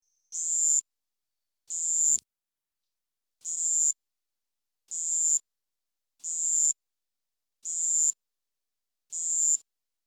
斑腿双针蟋